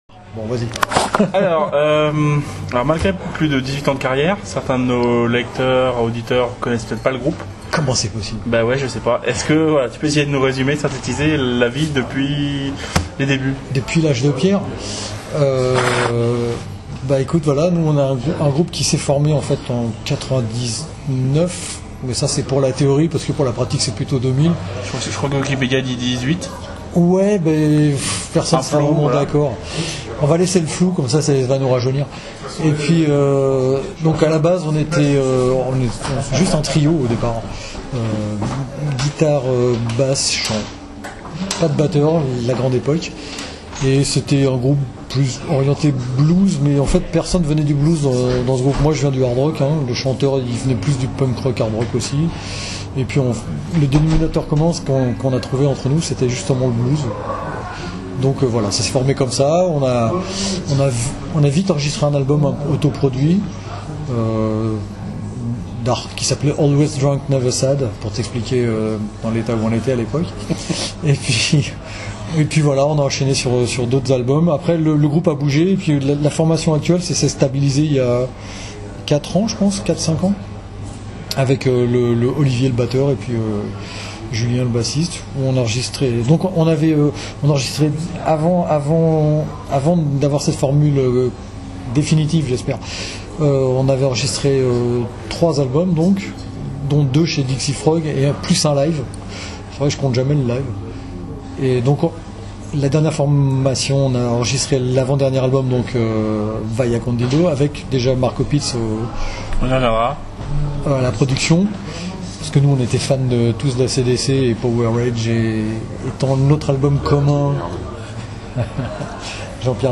JESUS VOLT (Interview